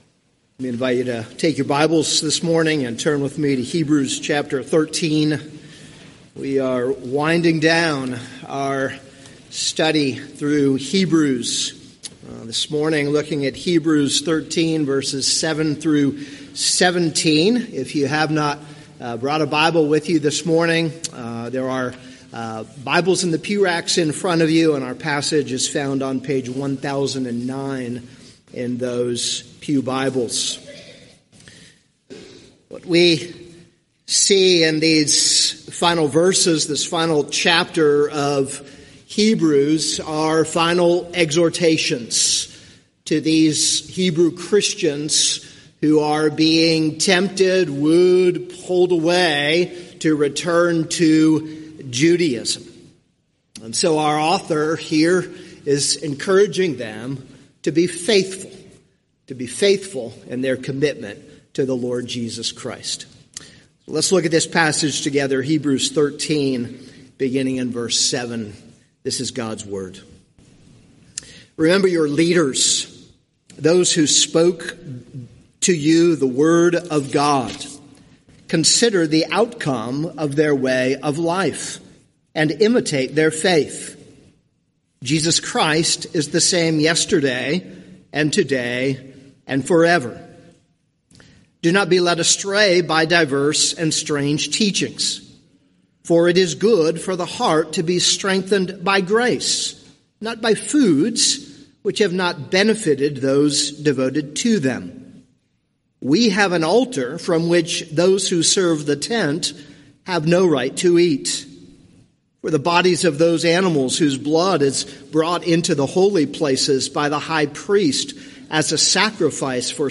This is a sermon on Hebrews 13:7-17.